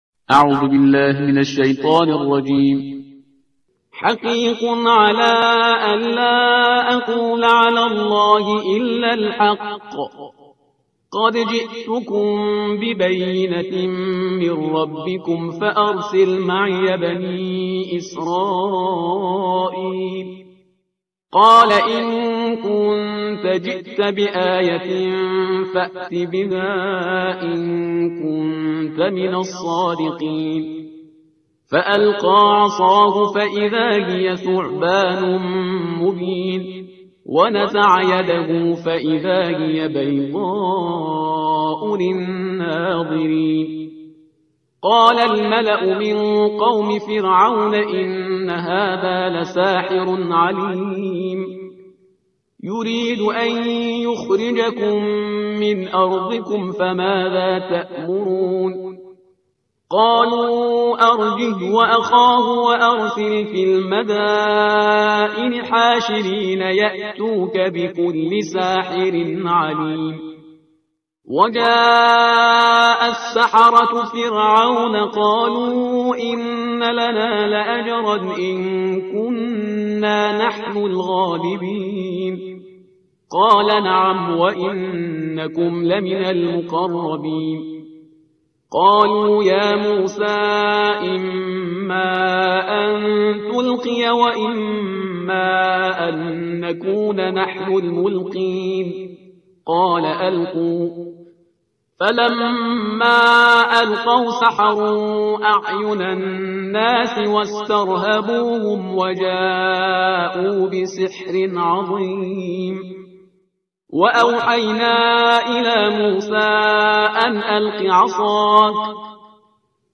ترتیل صفحه 164 قرآن